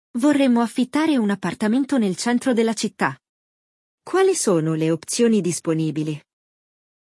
Ele apresenta um diálogo real sobre aluguel de apartamento, ensinando vocabulário essencial e ajudando na pronúncia e compreensão do idioma.